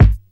• Hot Kick Single Shot G# Key 307.wav
Royality free steel kick drum sound tuned to the G# note. Loudest frequency: 176Hz
hot-kick-single-shot-g-sharp-key-307-0QY.wav